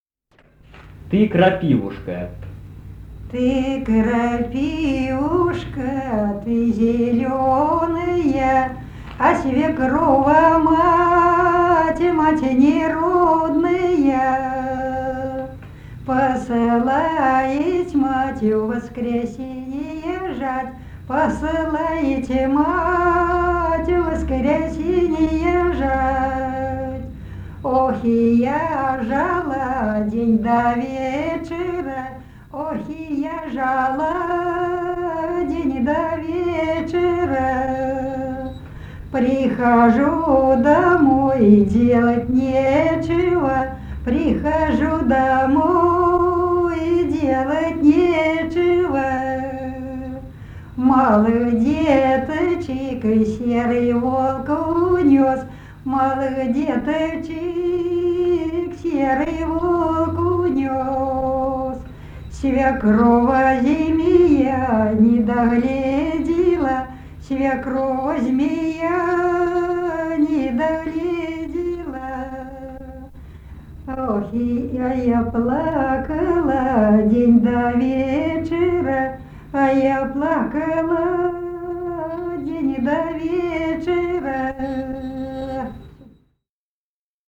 Народные песни Смоленской области
«Ты, крапивушка» (жнивная, баллада).